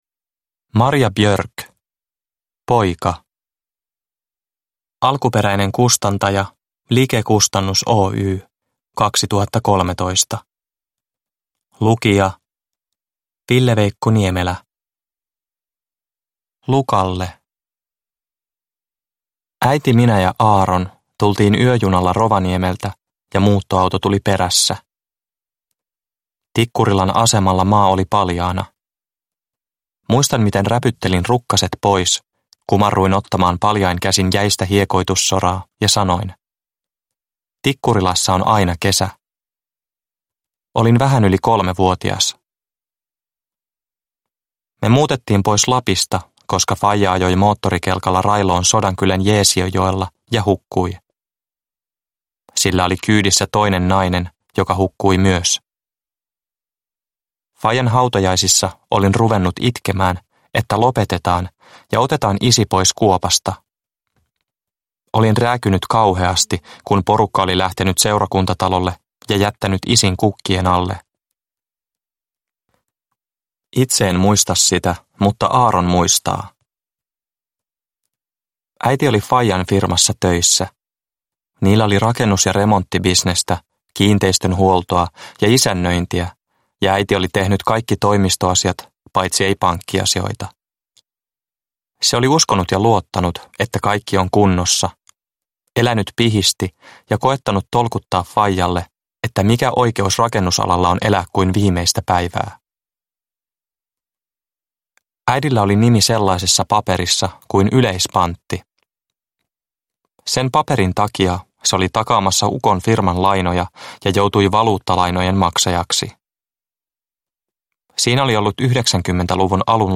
Poika – Ljudbok – Laddas ner